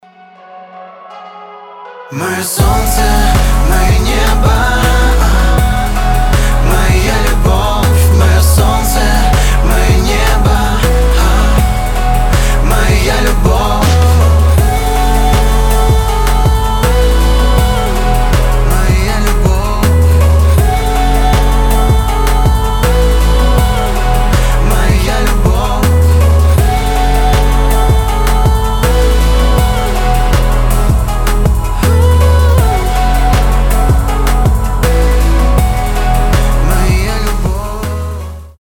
поп
громкие
мелодичные
красивый мужской вокал
романтичные